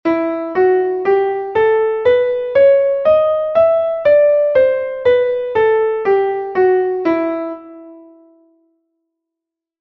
melodica.mp3